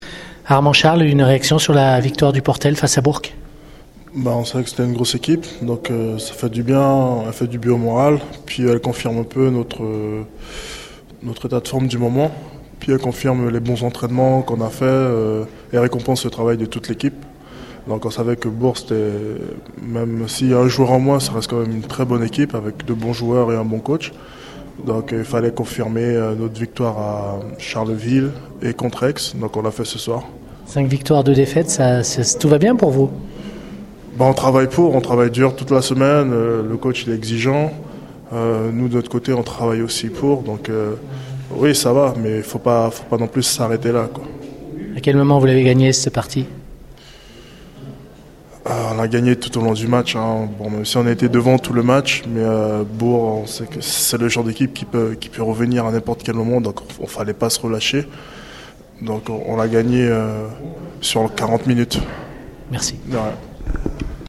Réactions des joueurs à la fin du match au Portel - JL Bourg Basket